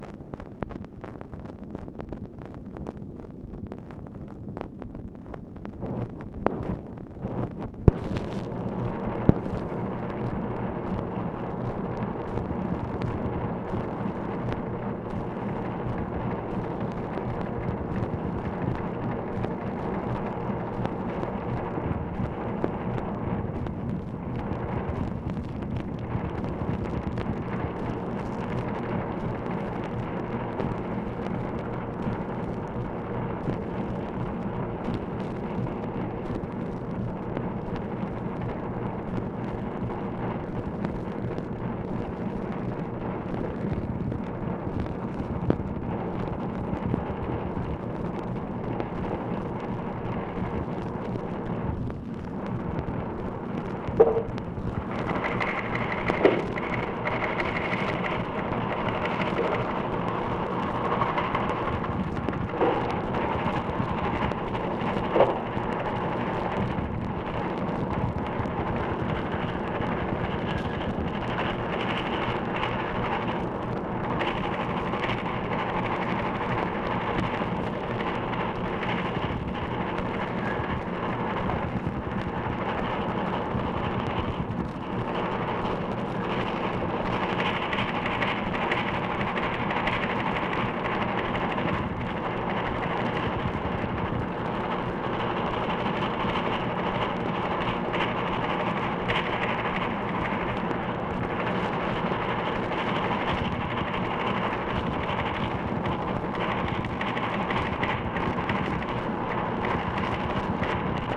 OFFICE NOISE, April 30, 1965
Secret White House Tapes | Lyndon B. Johnson Presidency